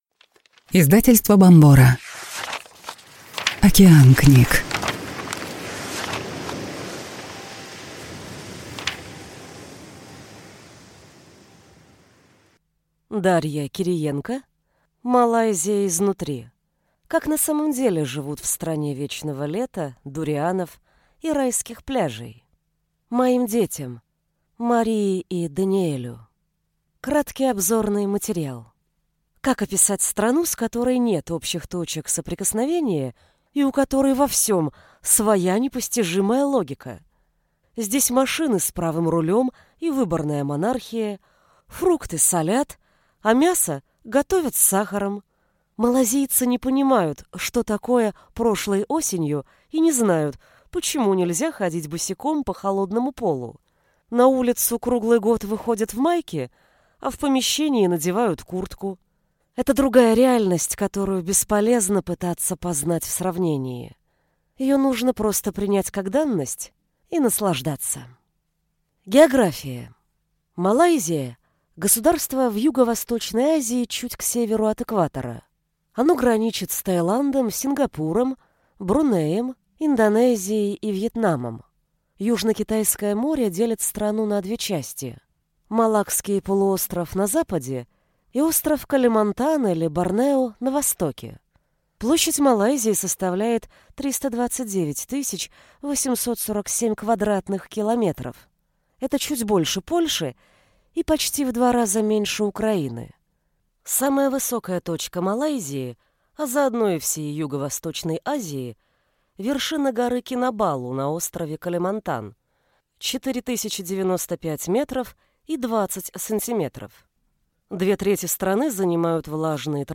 Аудиокнига Малайзия изнутри. Как на самом деле живут в стране вечного лета, дурианов и райских пляжей?